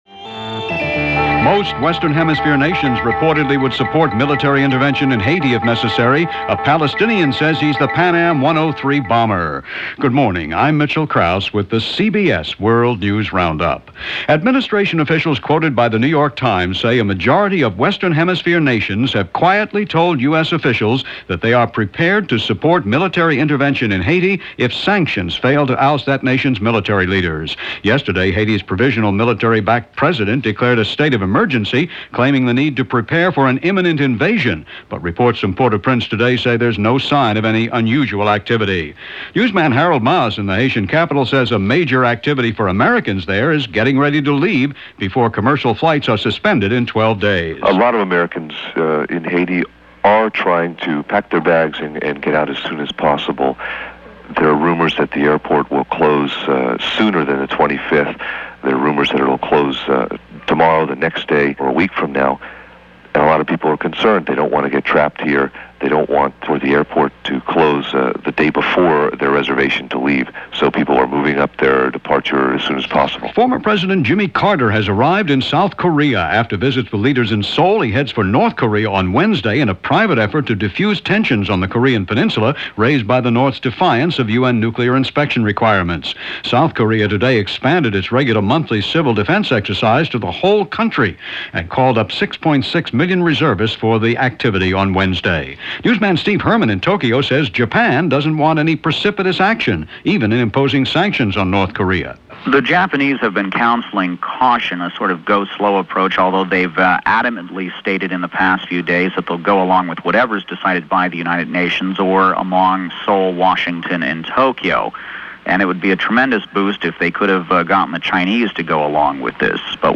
Holiday In Haiti - Message From Chiapas - June 13, 1994 – Past Daily: A Sound Archive of News, History And Music